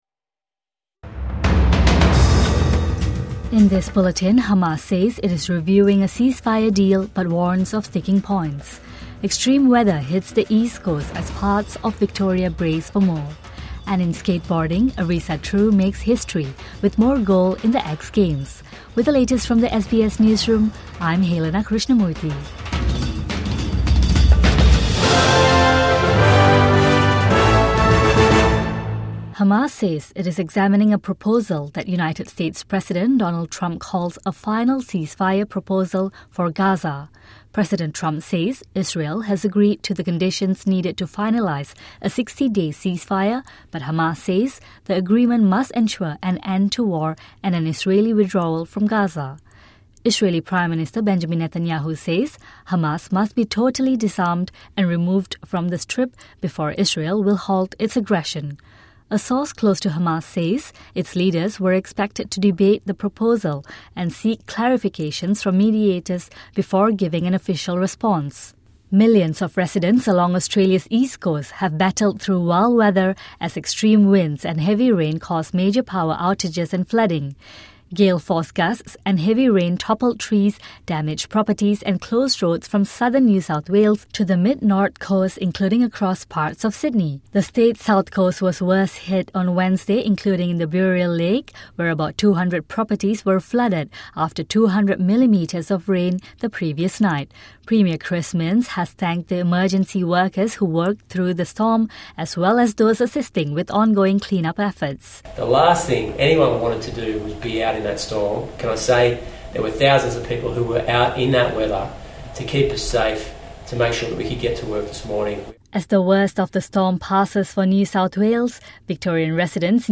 Hamas reviews ceasefire proposal for Gaza | Morning News Bulletin 3 July 2025